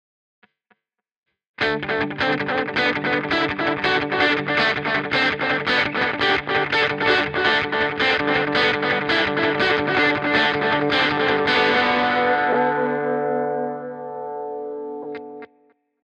• Con Delay: